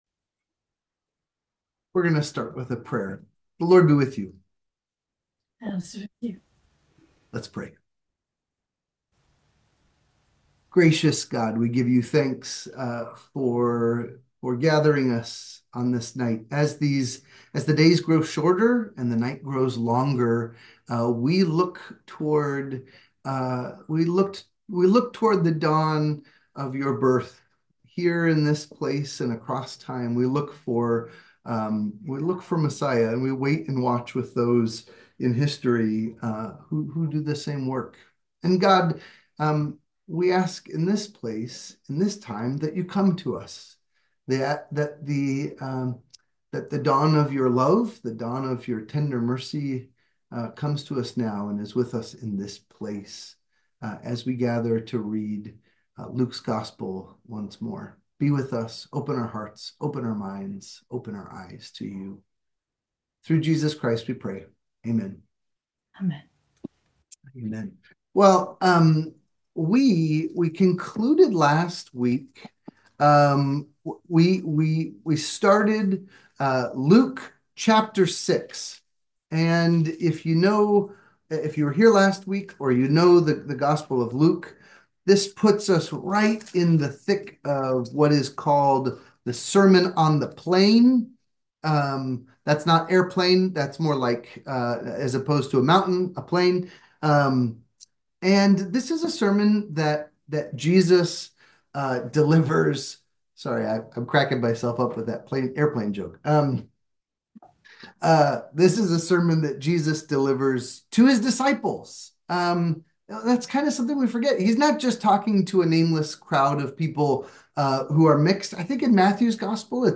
Goats Bible Study